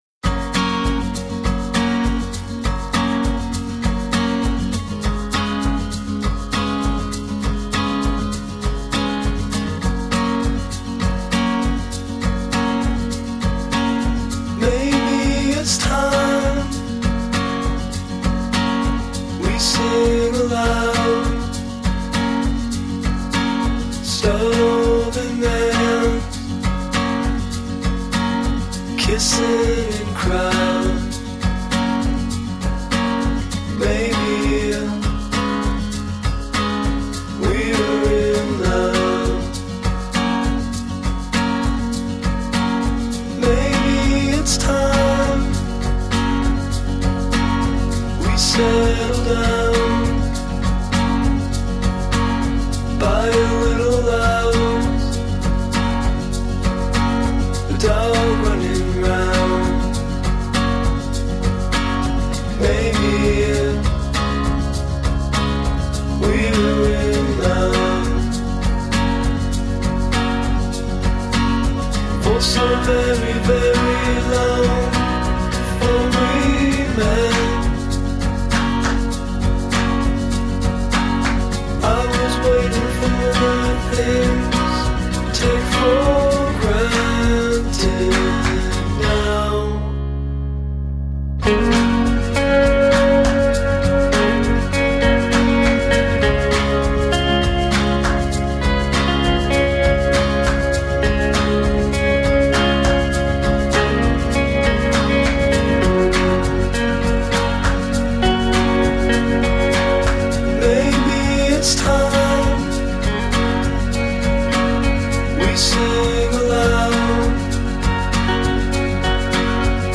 Melody-driven indie-folk.
folk-pop album
Tagged as: Alt Rock, Folk-Rock